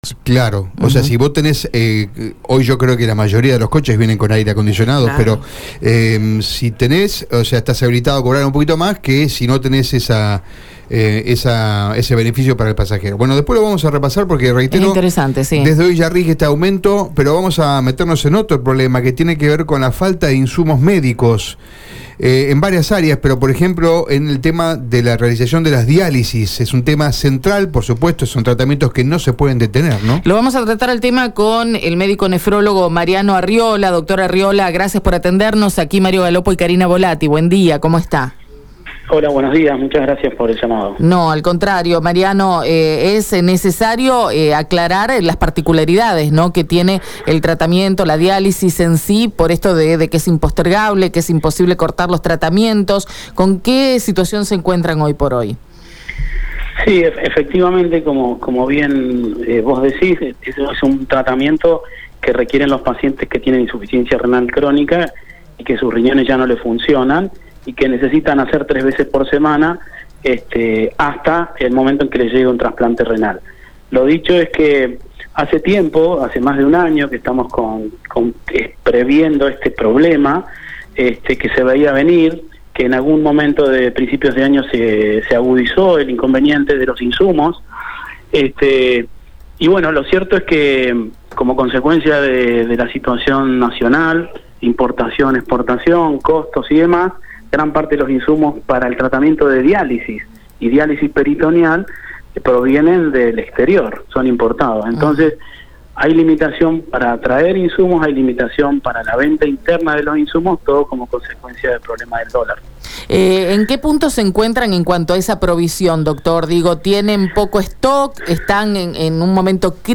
Por otro lado, el entrevistado remarcó que «En estos momentos hay poco stock, hay poco insumo.